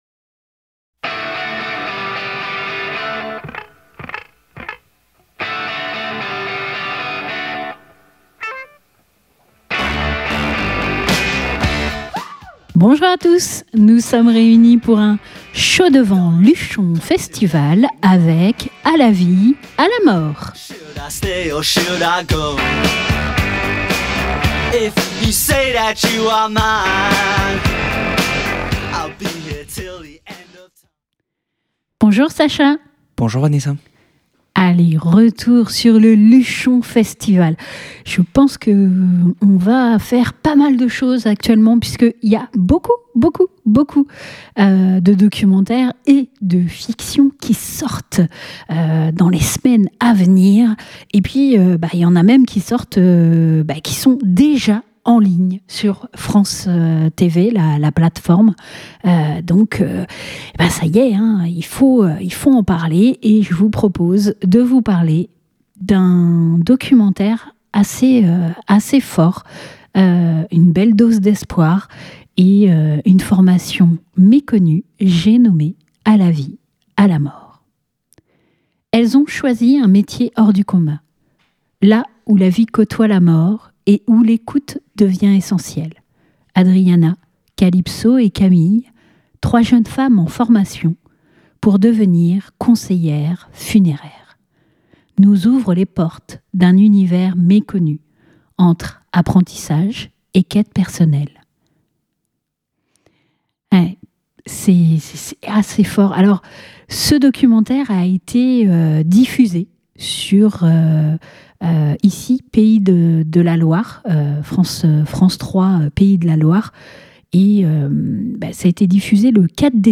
Ces derniers nous ont accordé une interview. "À la vie à la mort", mettant en avant des jeunes femmes en formation pour devenir conseillère funéraire, est disponible sur la plateforme France TV.